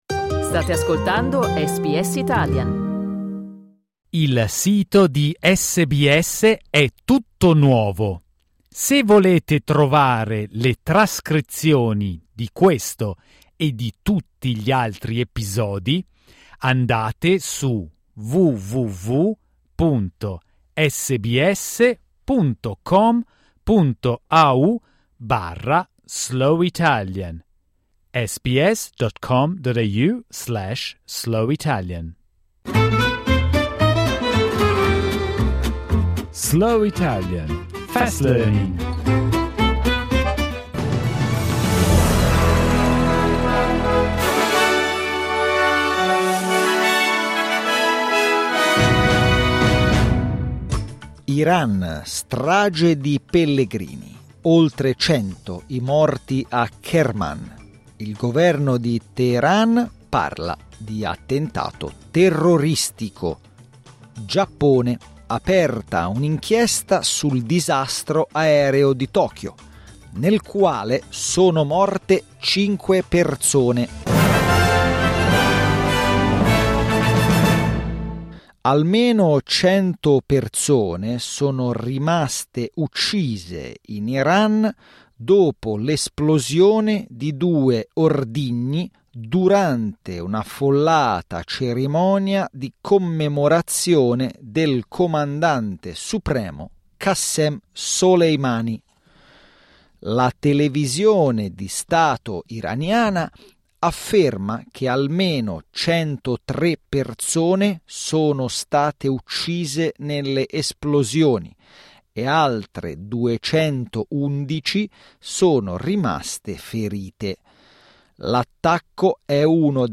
SBS Italian's News bulletin, read slowly.